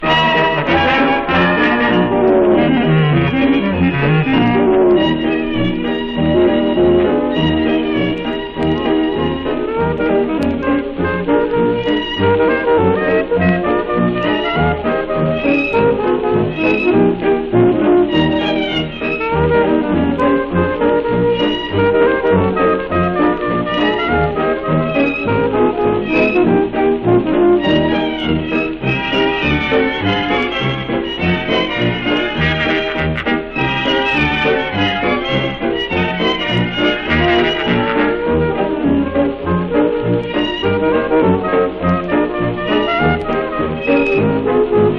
Jazz　France　12inchレコード　33rpm　Mono